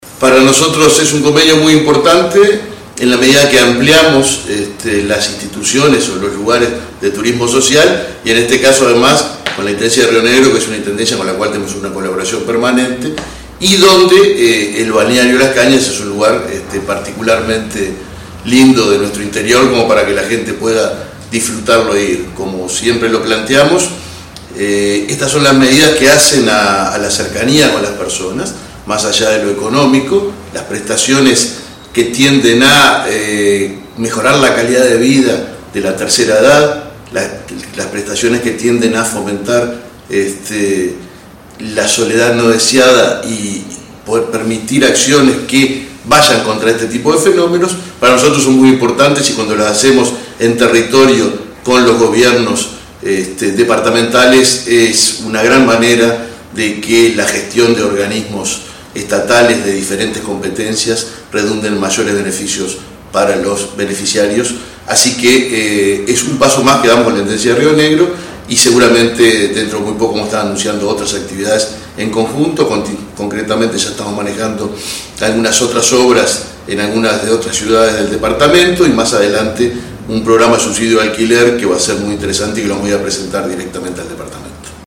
Declaraciones del presidente del BPS, Alfredo Cabrera